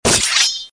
SFX大侠收剑声音音效下载
SFX音效